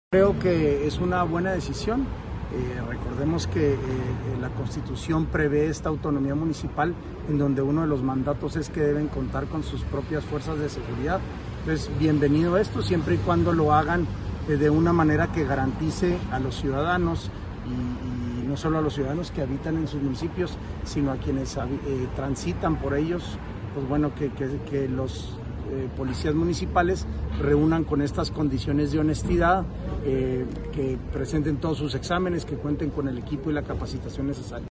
AUDIO: SANTIAGO DE LA PEÑA GRAJEDA, SECRETARÍA GENERAL DE GOBIERNO (SGG)